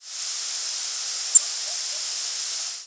Descending seeps
American Tree Sparrow
The "descending seep" group is a large species complex in eastern North America whose flight calls are above 6KHz in frequency and are descending in pitch.